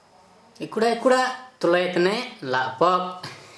Dialect: Hill-Plains mixed/transitional